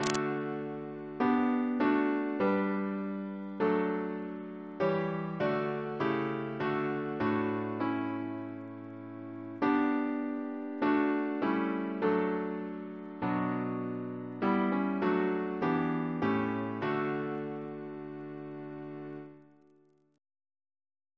Double chant in D Composer: Stephen Elvey (1805-1860), Organist of New College, Oxford; George's brother Reference psalters: ACB: 94; ACP: 293; CWP: 62; OCB: 27; PP/SNCB: 200; RSCM: 50